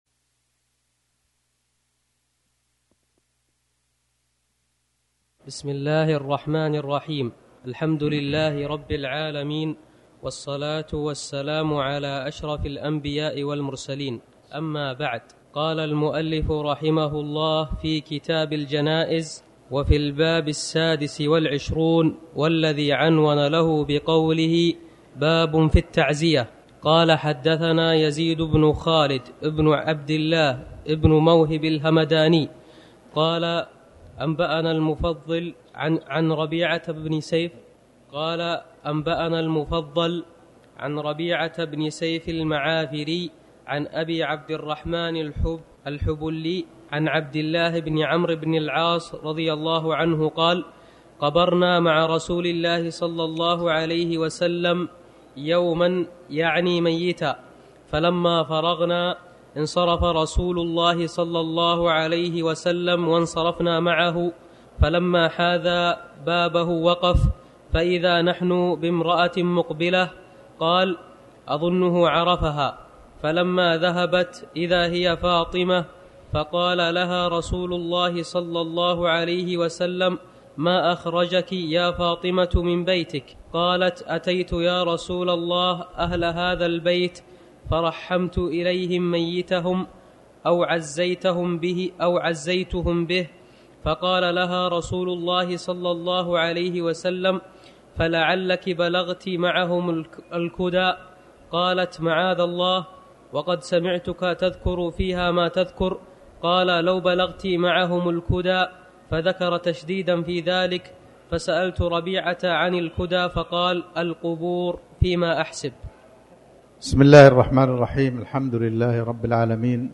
تاريخ النشر ٤ صفر ١٤٤٠ هـ المكان: المسجد الحرام الشيخ